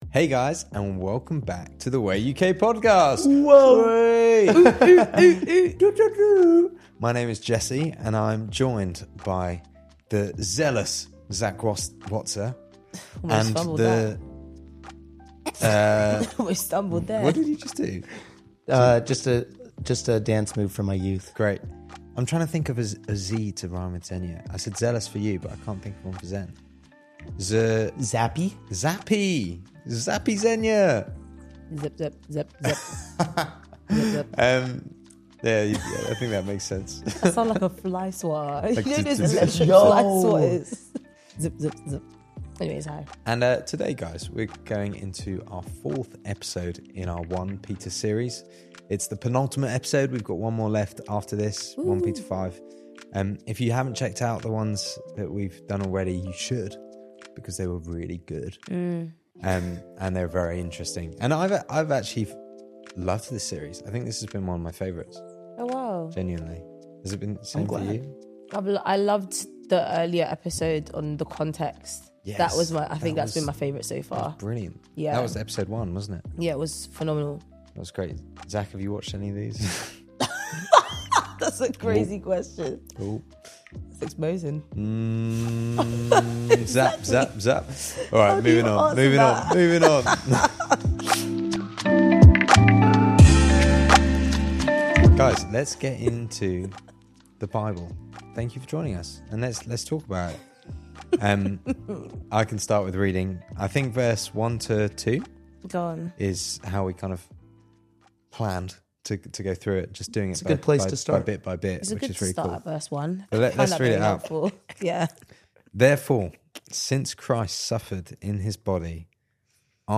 1 PETER 4 | BIBLE STUDY